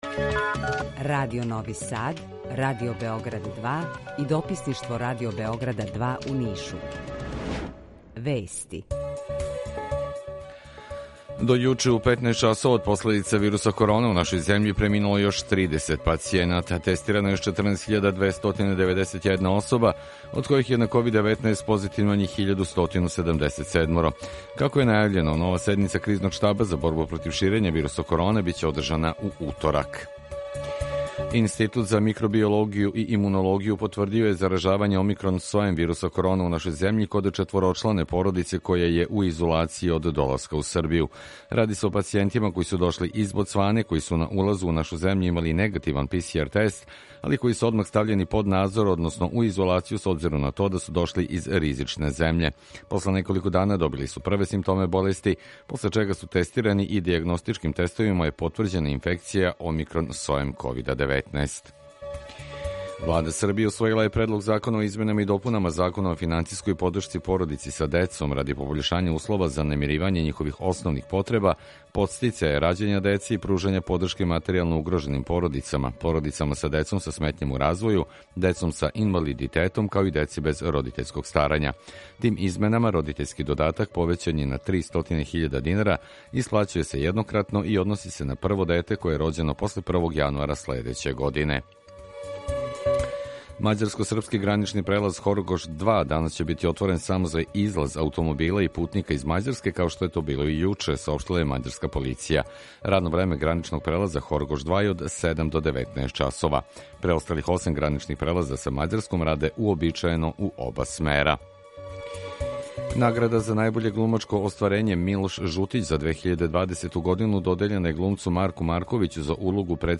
Четири градоначелника уживо у четири студија - Београд, Нови Сад, Ниш, Бањалука
У два сата, ту је и добра музика, другачија у односу на остале радио-станице.